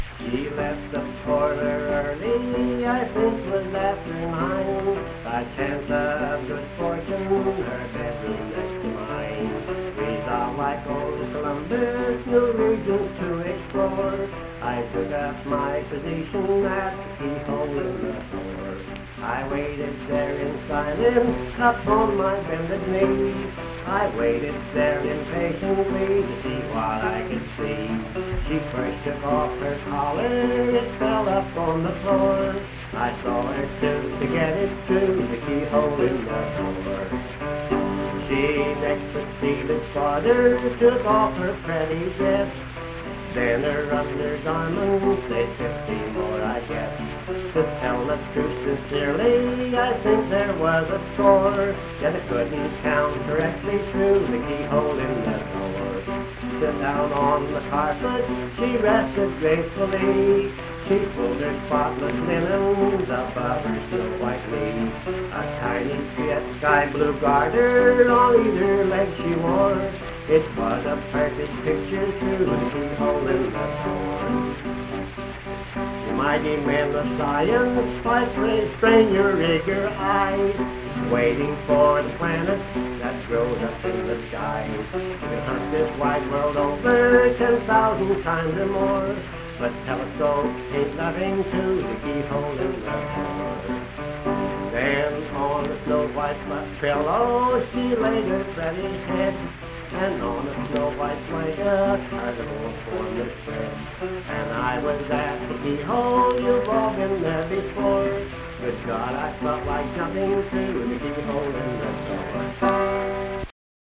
Accompanied voice and guitar music
Performed in Hundred, Wetzel County, WV.
Bawdy Songs
Voice (sung), Guitar